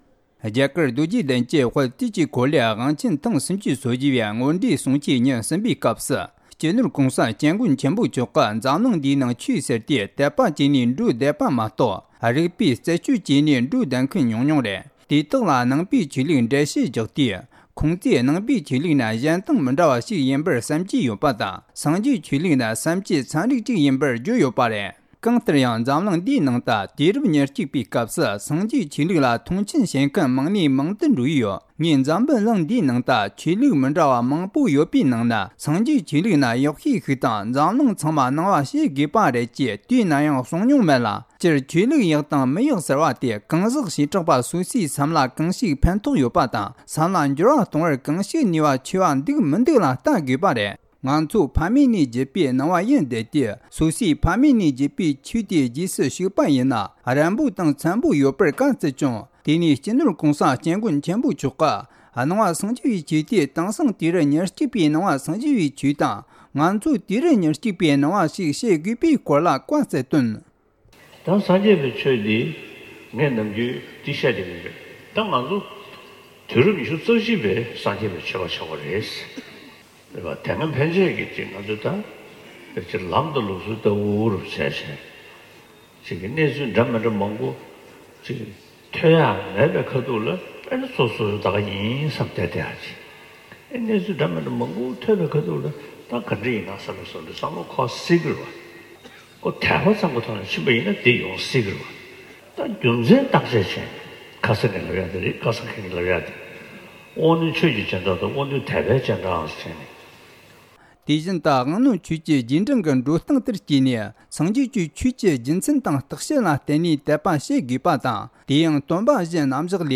༧གོང་ས་མཆོག་ནས་བཟོད་པའི་ཉམས་ལེན་སྐོར་བཀའ་སློབ་བསྩལ་བ། ༧གོང་ས་མཆོག་ནས་ཕྱི་ཚེས་ ༧ ཉིན་སྤྱོད་འཇུག་ཆེན་མོའི་ལེའུ་ལྔ་པ་ཤེས་བཞིིན་གྱི་ལེའུ་དང་། བཟོད་པའི་ལེའུ། བསམ་གཏན་གྱི་ལེའུ་། དེ་བཞིན་སྒོམ་རིམ་བར་པའི་ཞི་གནས་སྐོར་གྱི་རིམ་པ་བཅས་ཀྱི་སྒོ་ནས་བཤད་ལུང་སྩོལ་བཞིན་པ།